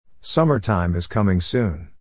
Englisch lernen mit den Wichteln in kurzen, einfachen Lektionen mit Hörbeispielen der Aussprache.